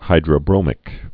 (hīdrə-brōmĭk)